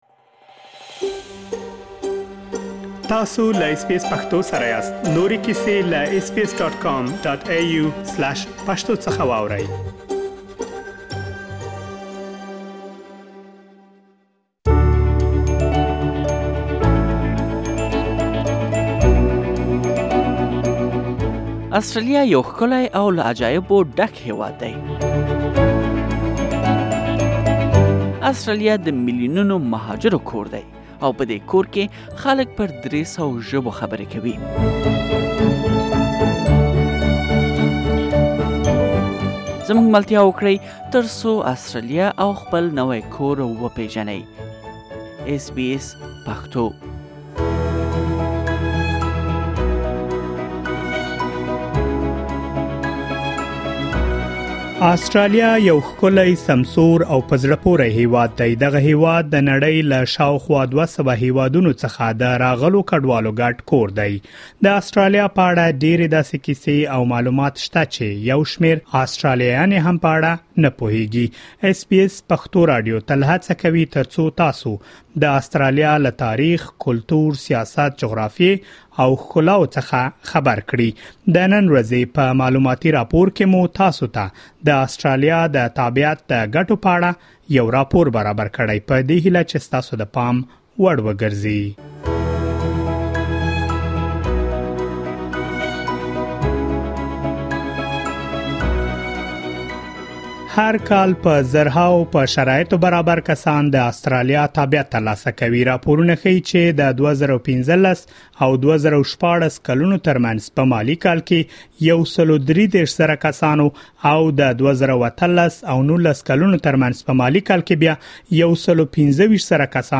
د اسټراليا تابعيت ترلاسه کول د ډير مهاجرو يو ستر خوب وي، مونږ تاسو ته د اسټراليا تابعيت ټولې ګتې په رپوټ کې راخيستي.